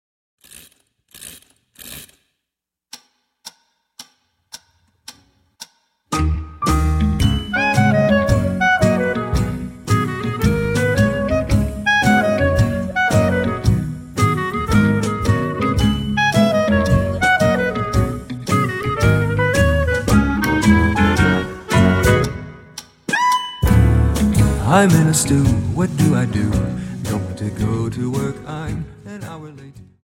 Dance: Slowfox Song